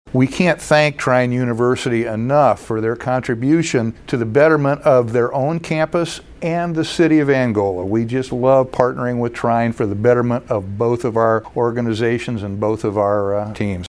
Angola Mayor Dave Martin says the City is proud to partner with Trine on this project.